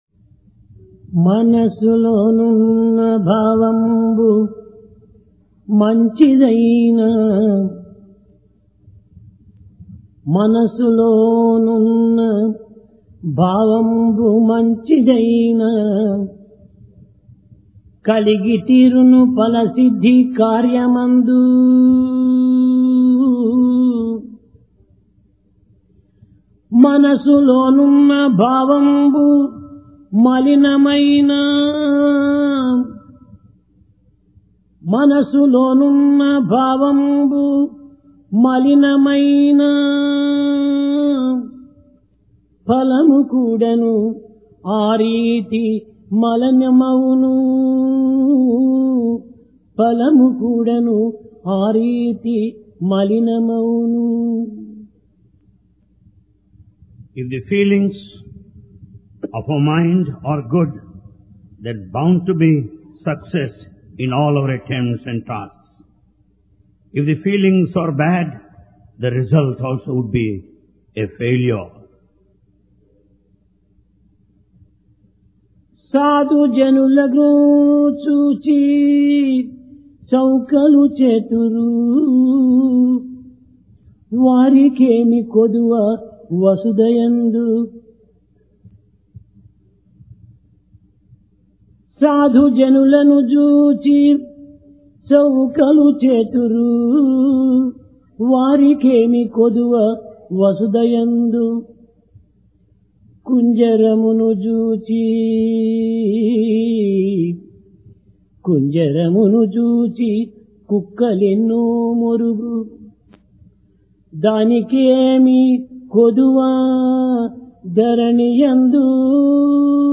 Sai Darshan Home Date: 13 Jul 1996 Occasion: Divine Discourse Place: Prashanti Nilayam Need For Love And Truth If inner motives are good, the fruits of our action will be beneficial or successful.